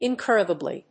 音節ìn・cór・ri・gi・bly 発音記号・読み方
/‐dʒəbli(米国英語)/